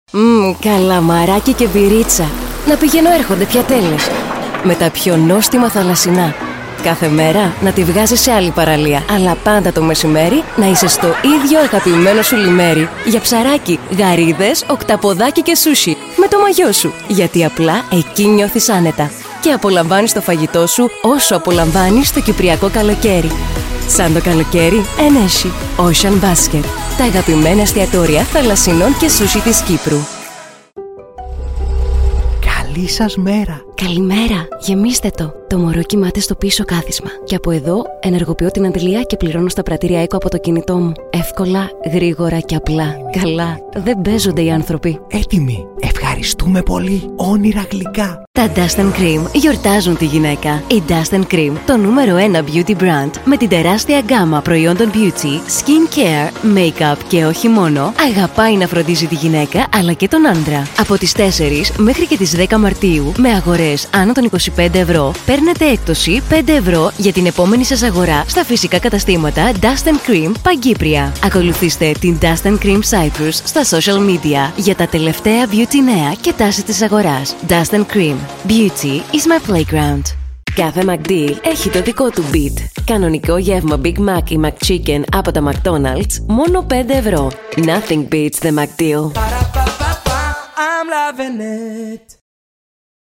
Griekse voice-over
Commercieel